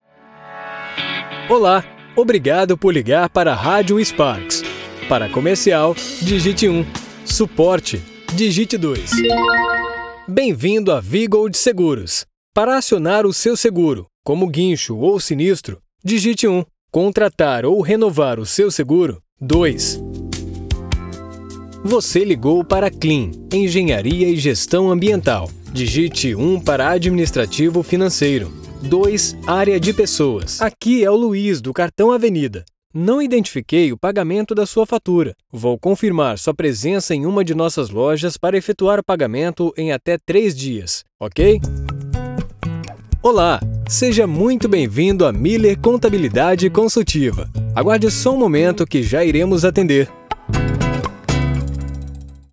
Masculino
Voz Padrão - Grave 00:52
Além de equipamentos profissionais devidamente atualizados, todas as locuções são gravadas em cabine acústica, resultando em um áudio limpo e livre de qualquer tipo de interferência.